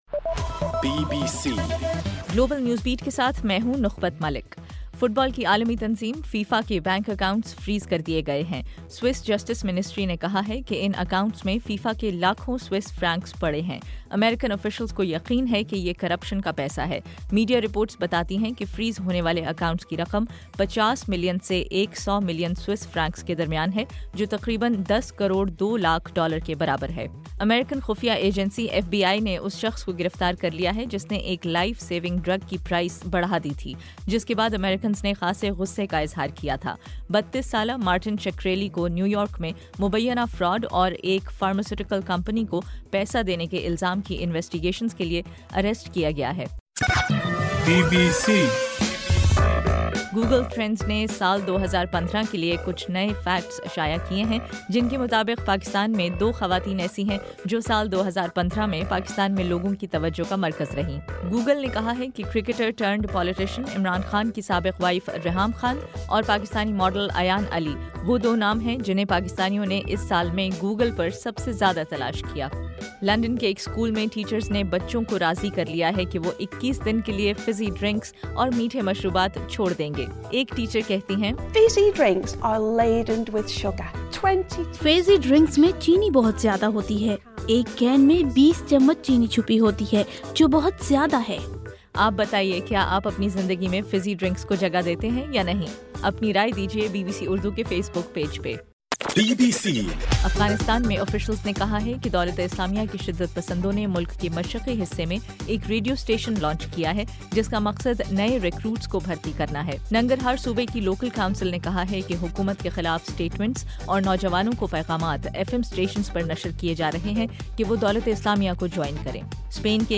دسمبر 17 رات 9 بجے کے گلوبل نیوز بیٹ بلیٹن